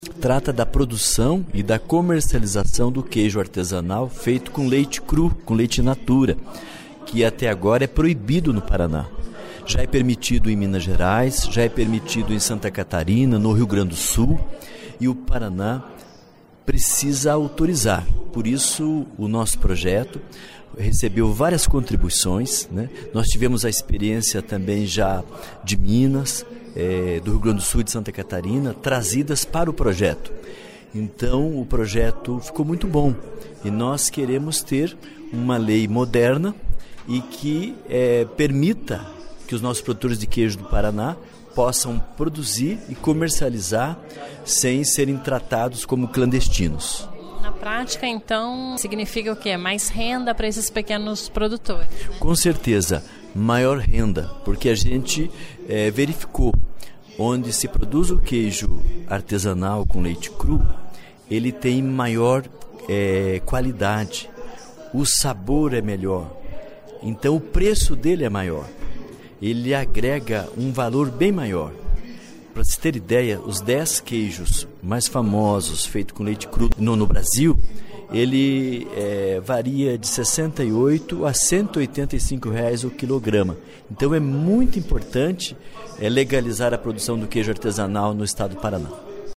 Ouça entrevista com o autor do projeto, o deputado Professor Lemos (PT).